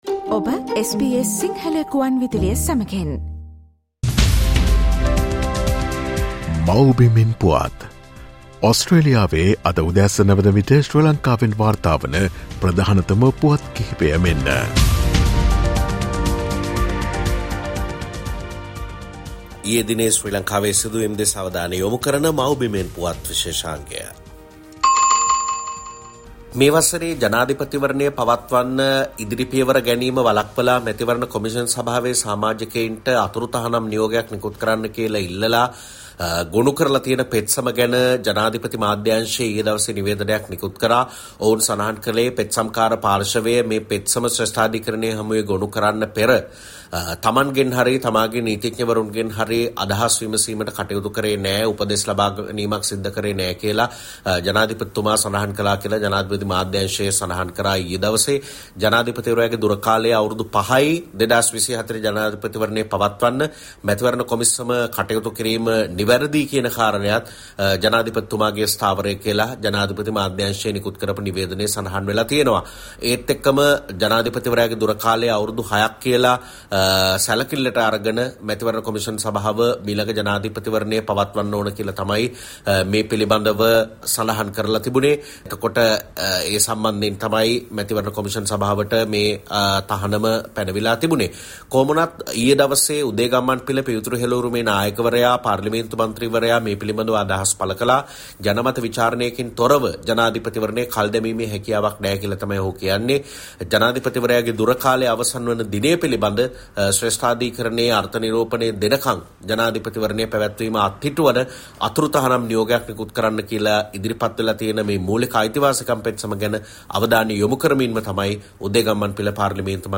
SBS Sinhala featuring the latest news reported from Sri Lanka